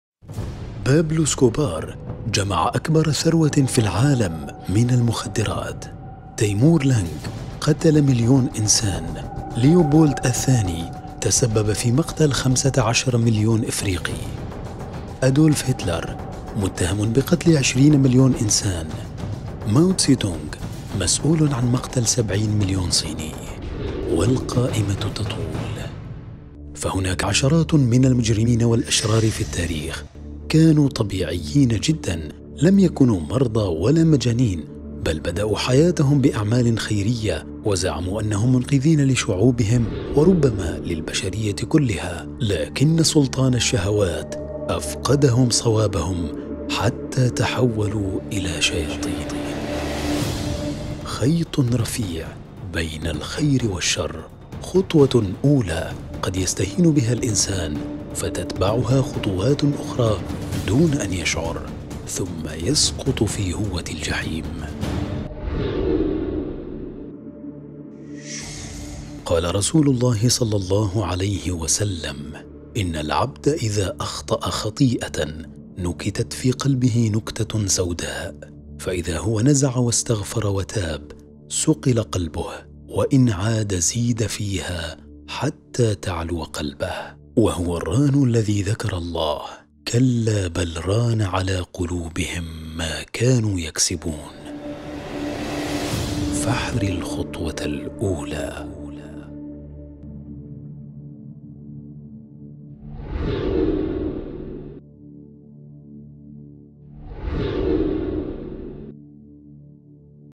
تعليق ومؤثرات صوتية